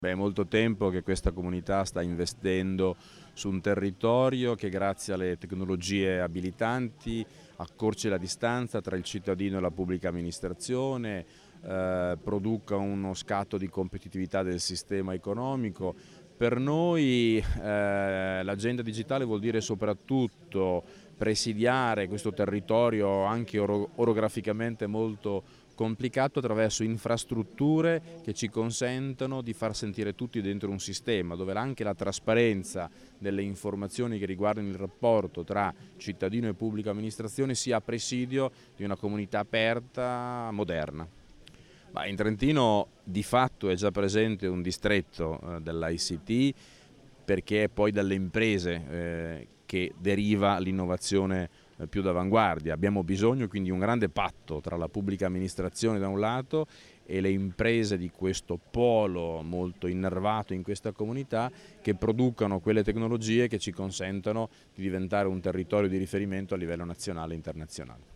INTERVISTA_OLIVI_Trento_smart_city.mp3